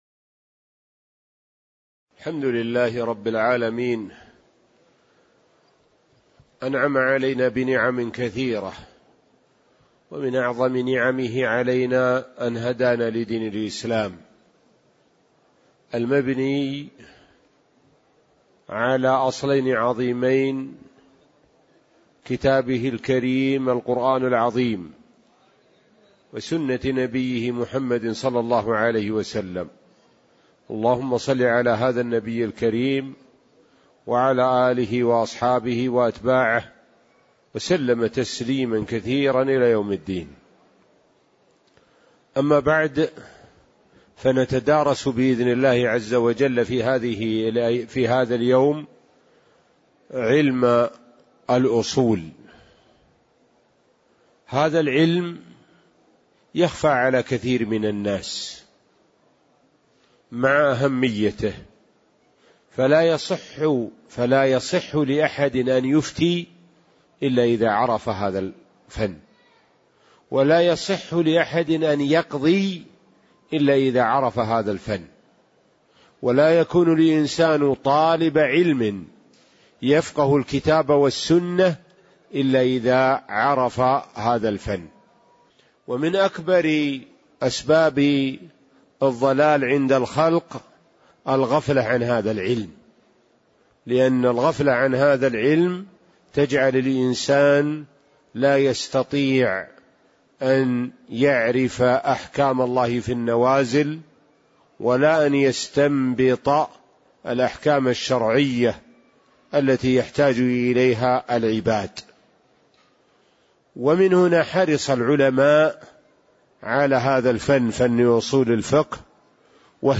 تاريخ النشر ٢٥ شوال ١٤٣٧ هـ المكان: المسجد النبوي الشيخ: معالي الشيخ د. سعد بن ناصر الشثري معالي الشيخ د. سعد بن ناصر الشثري المقدمة (01)